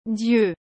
O som é algo como “diê”, com um final levemente nasalizado.